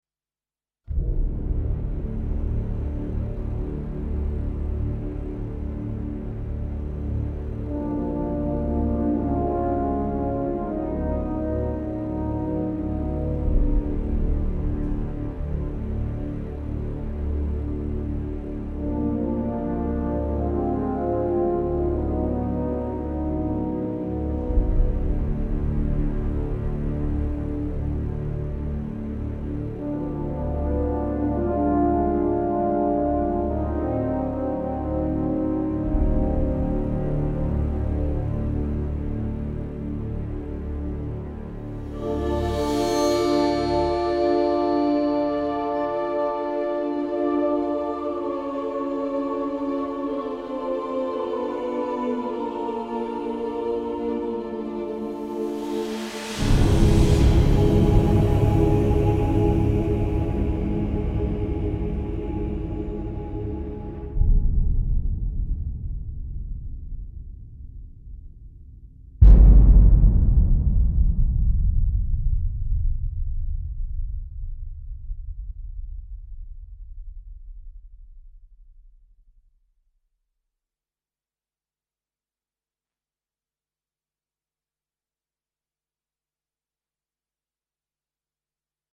• Más de 50 GB de contenido cinematográfico
DESCIENDE A LOS RINCONES OSCUROS